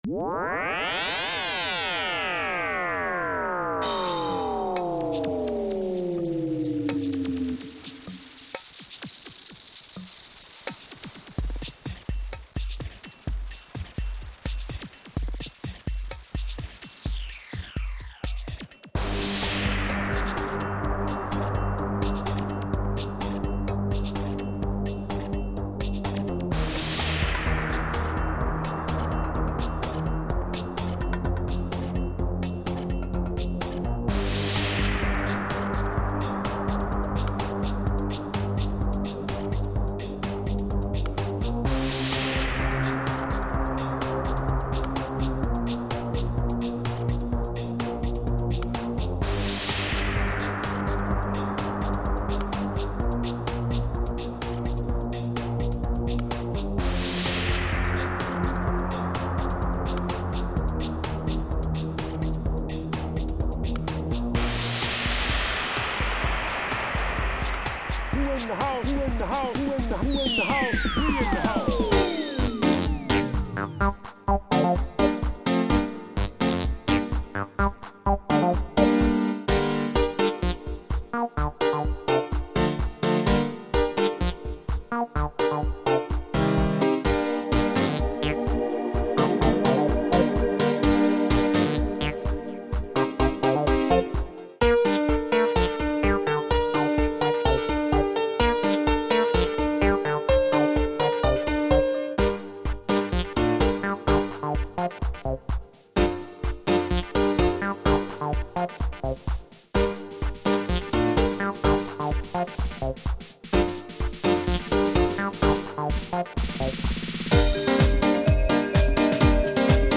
Nicht-Klassik
Hat so nen bisschen was von Zukunftsvision und Weltraumspiel